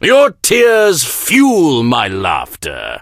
snakeoil_kill_vo_03.ogg